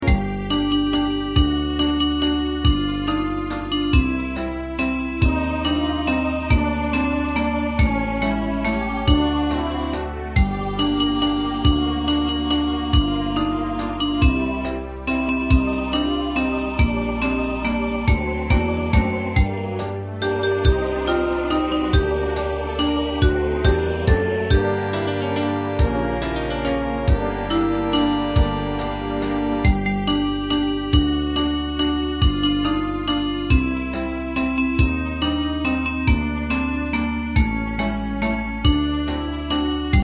大正琴の「楽譜、練習用の音」データのセットをダウンロードで『すぐに』お届け！
カテゴリー: ユニゾン（一斉奏） .
映画音楽・軽音楽